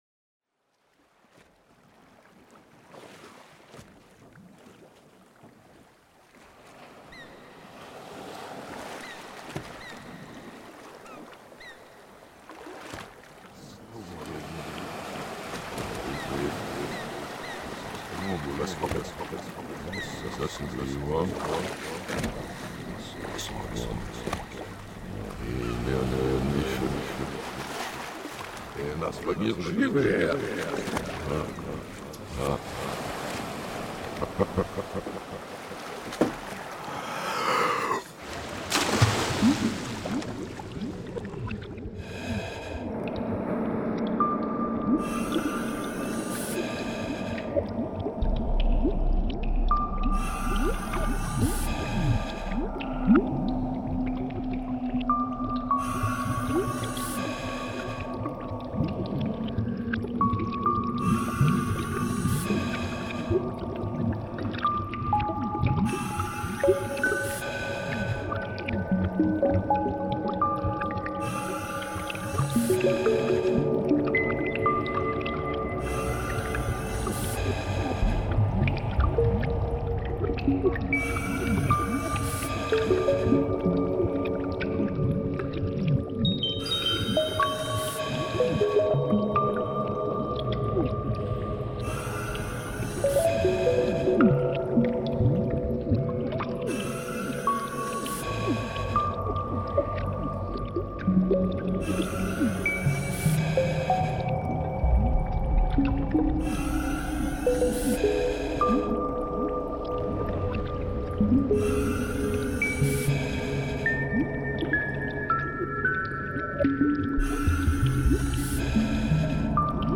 Ce morceau sans mélodie s’apparente plus à de la musique concrète.
C’est un paysage sonore marin…
ARP 2600, SH 101, Polivoks, JX 10, HS 60 et sons issus de ma sonothèque personnelle.